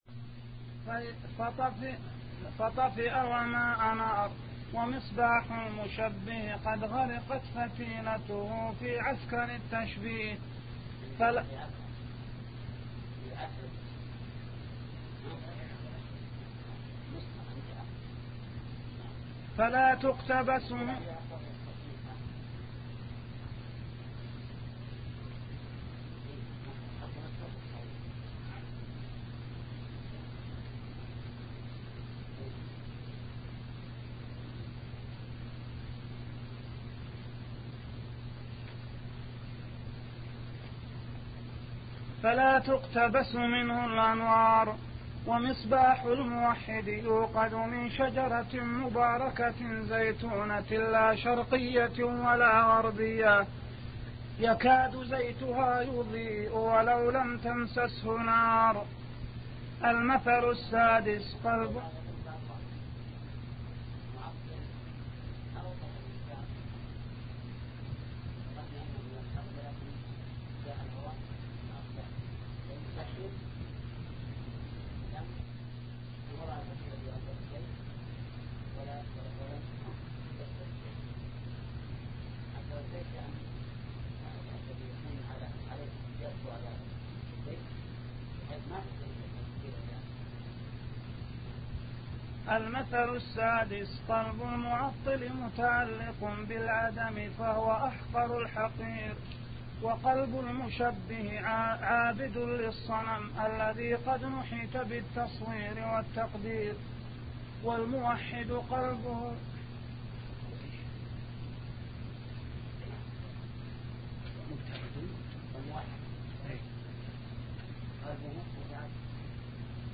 شبكة المعرفة الإسلامية | الدروس | التعليق على القصيدة النونية 2 |محمد بن صالح العثيمين